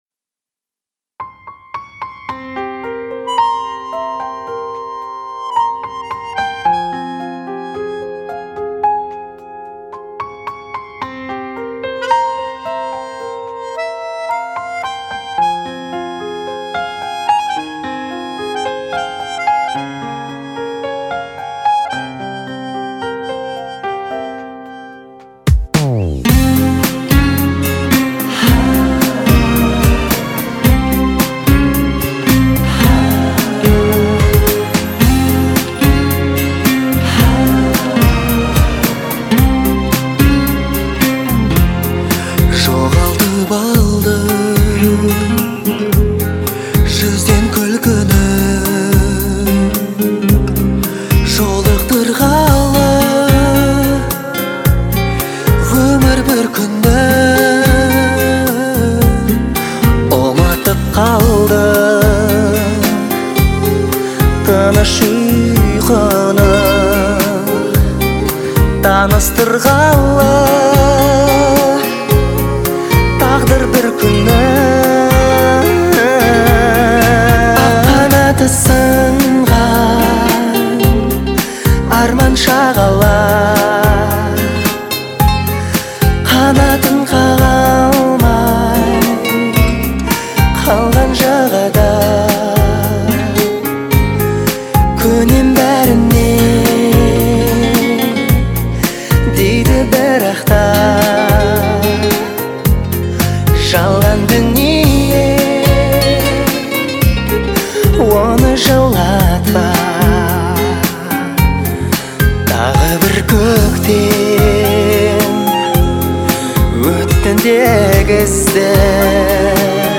это яркий пример казахского поп-музыки с элементами фолка.